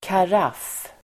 Ladda ner uttalet
Uttal: [kar'af:]
karaff.mp3